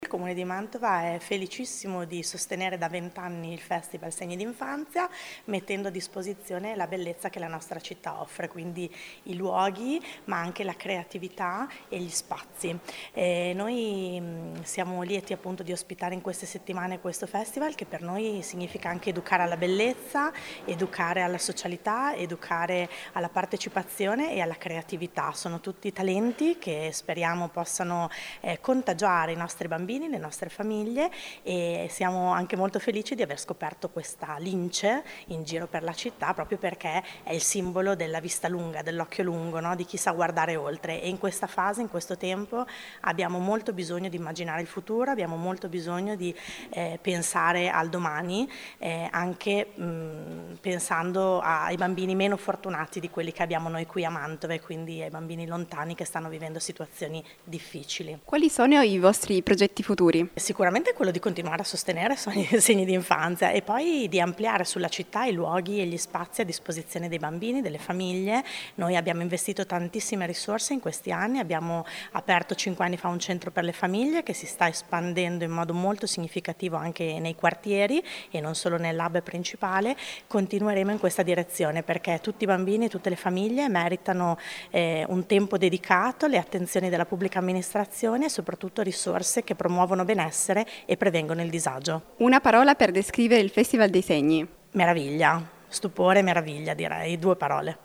Ascolta l’intervista a Chiara Sortino, Assessora Politiche e Servizi per la famiglia e Genitorialità, Infanzia e Adolescenza, Attività Educative e Ricreative per minori, Pari Opportunità: